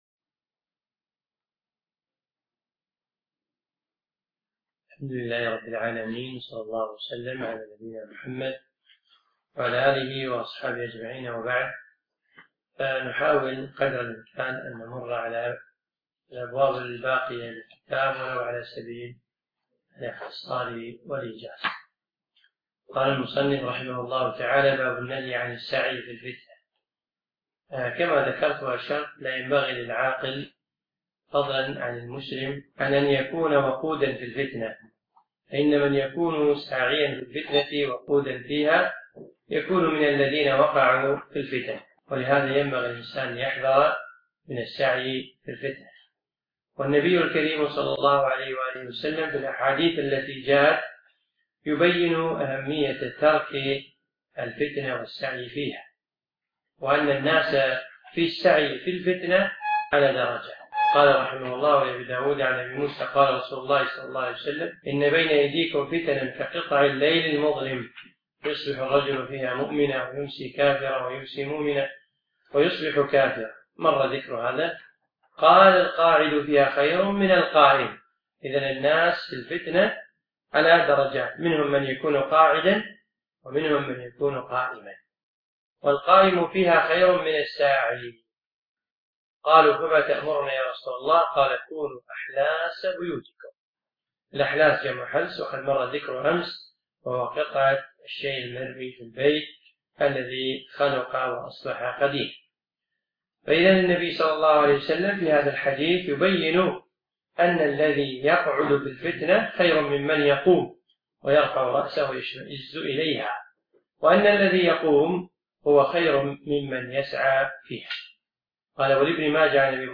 أقيمت الدورة في مركز القصر نساء مسائي